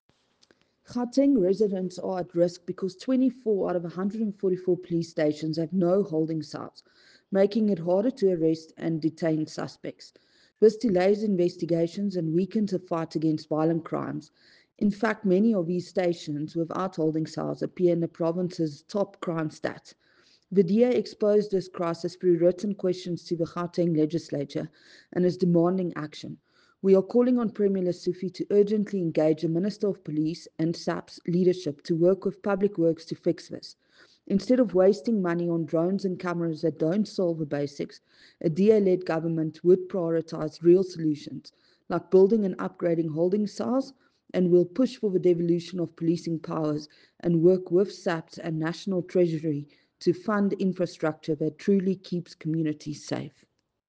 Afrikaans soundbites by Crezane Bosch MPL.